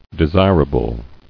[de·sir·a·ble]